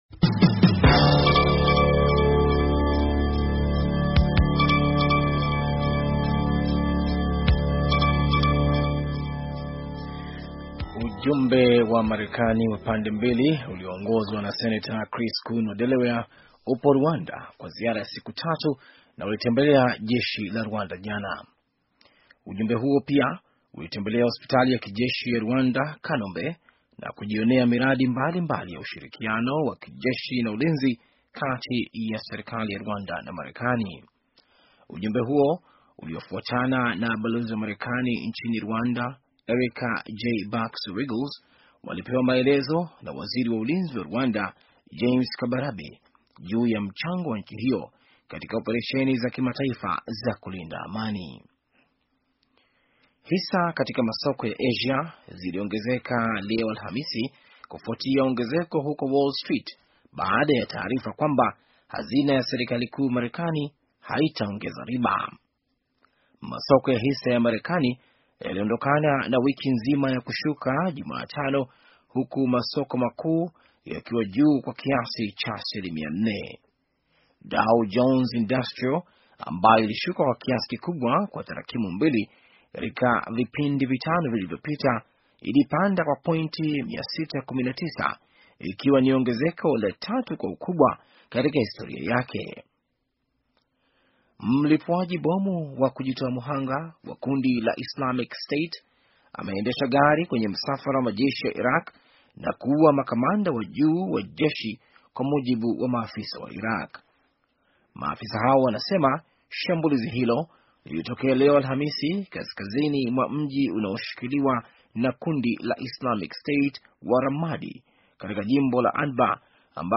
Taarifa ya habari - 5:36